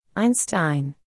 • For German words, the long “i” sound goes “ei” – like in Einstein (